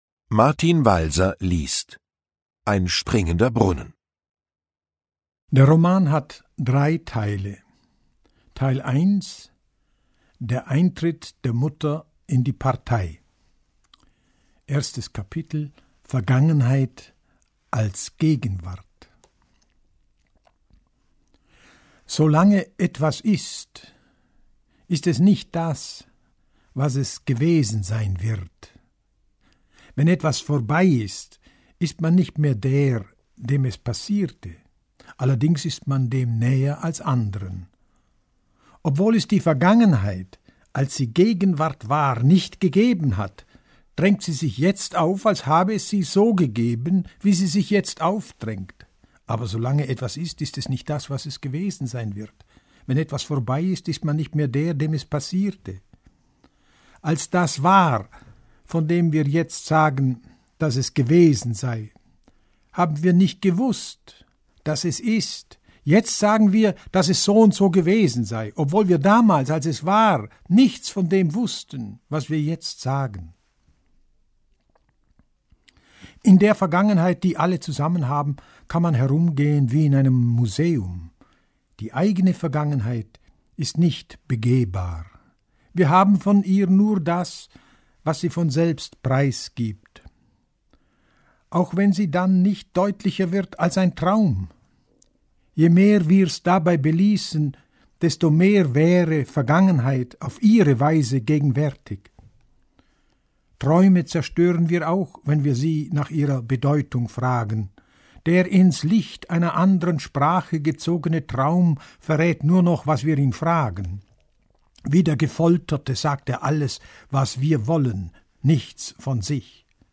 Ungekürzte Autorenlesung (2 mp3-CDs)
Martin Walser (Sprecher)